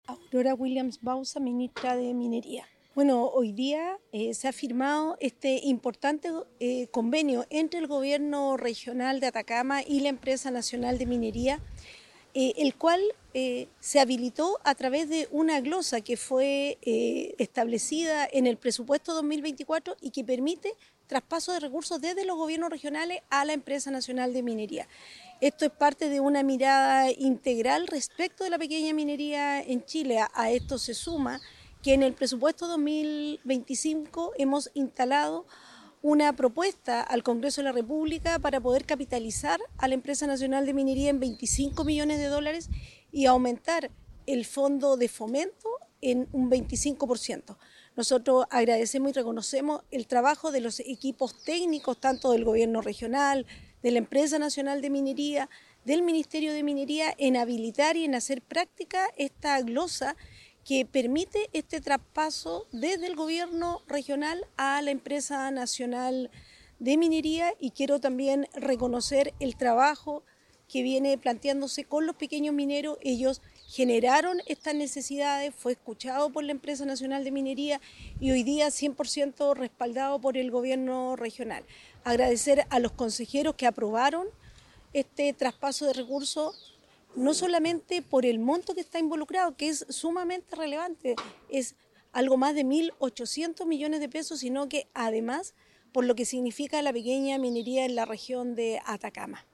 MINISTRA-DE-MINERIA.mp3